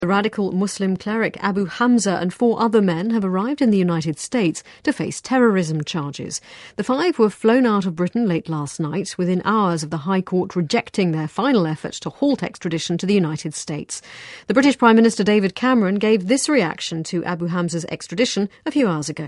【英音模仿秀】激进牧师被引渡回美 面临多项指控 听力文件下载—在线英语听力室